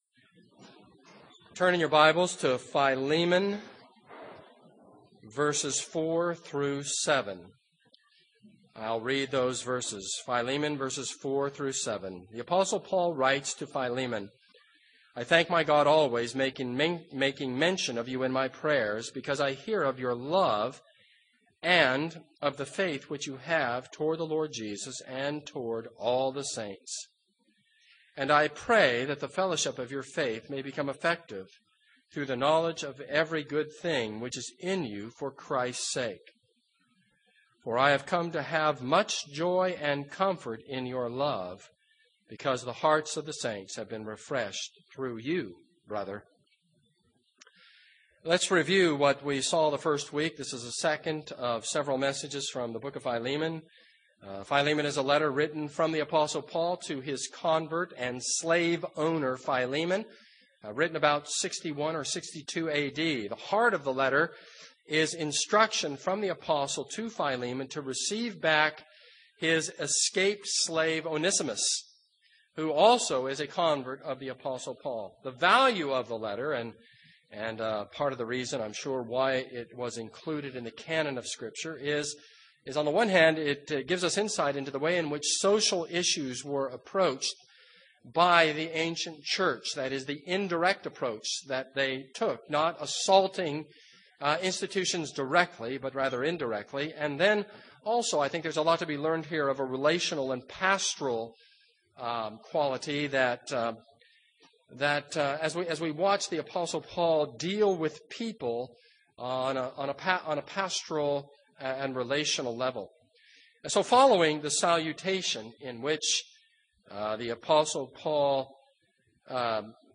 This is a sermon on Philemon 1:4-7.